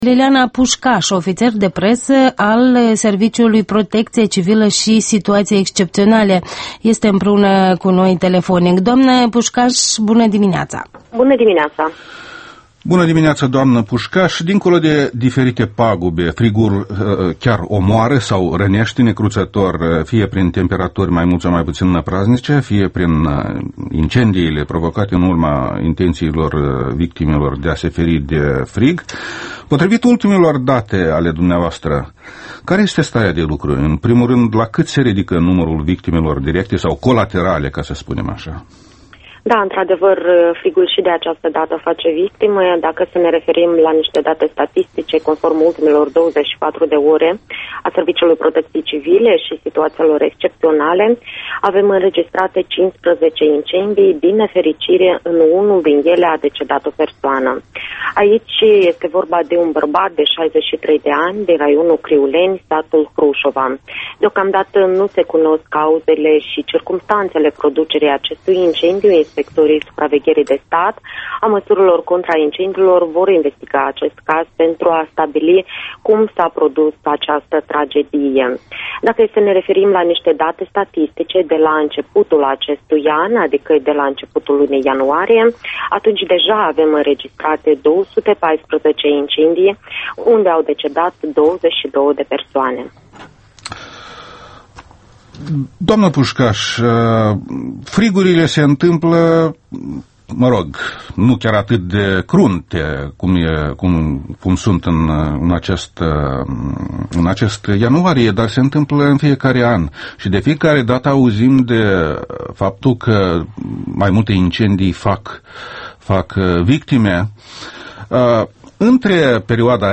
Interviul dimineții la Europa Liberă